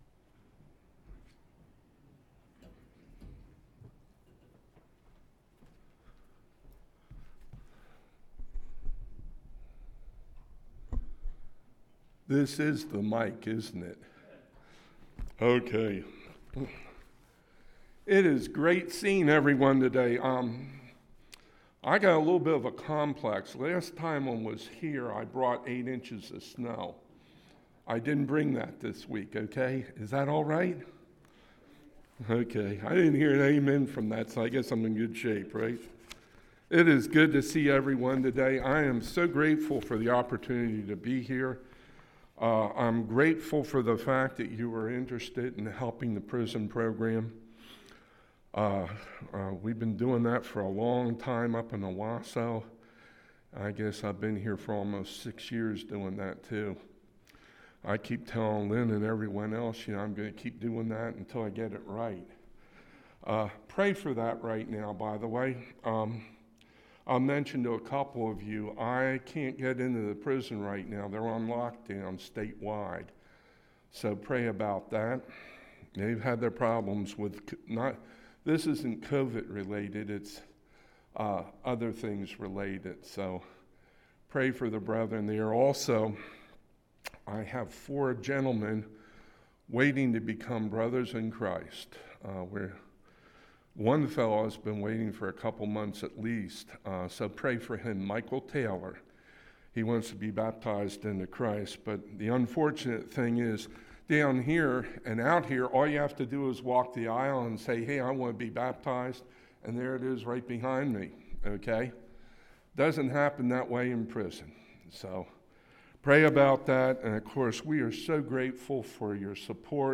God’s Censors? – Sermon